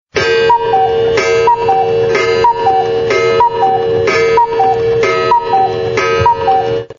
chasy-s-kukushkoi_24545.mp3